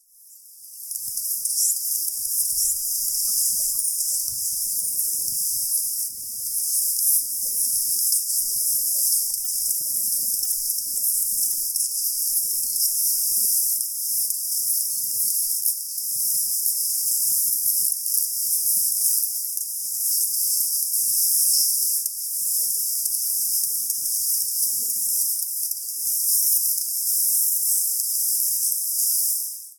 31.12.13 - remixed version done entirely by EQing.